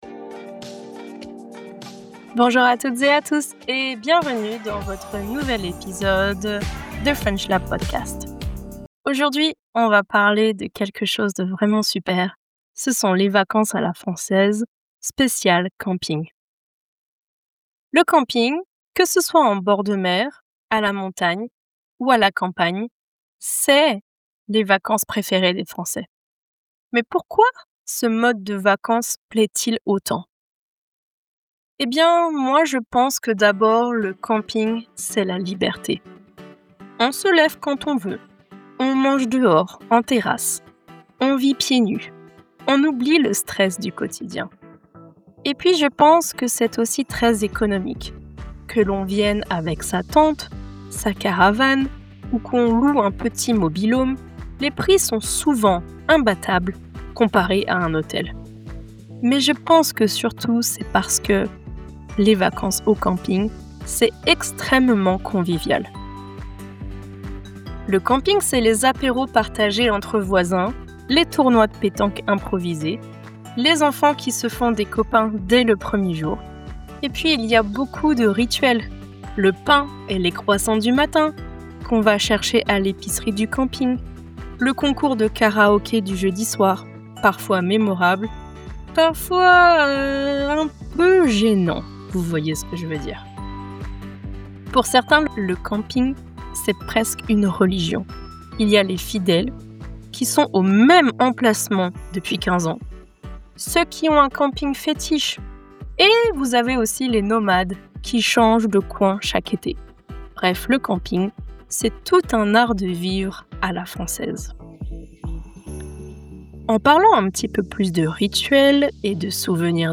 Slow-paced, clear pronunciation, and real-life vocabulary—perfect for learning on the go!